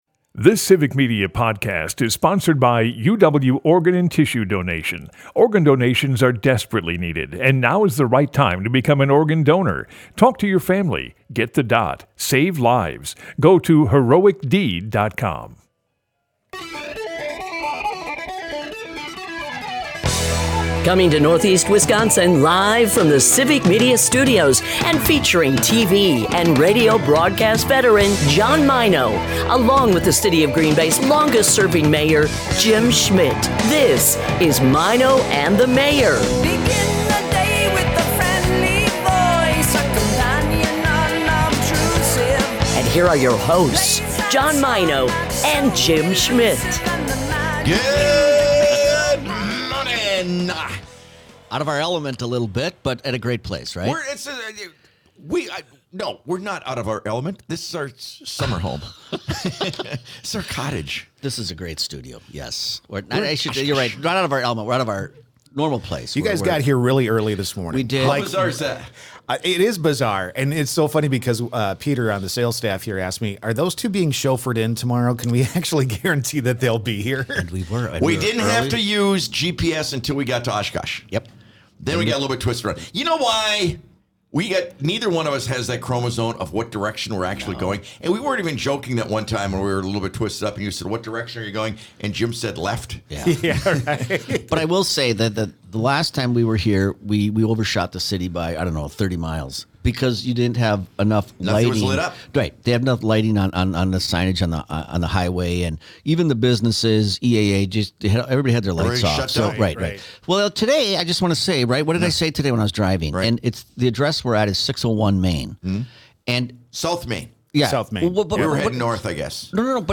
Broadcasts live 6 - 9am in Oshkosh, Appleton, Green Bay and surrounding areas.
Here We Come! 1/15/2025 Listen Share The guys actually show up early to our Oshkosh studios!!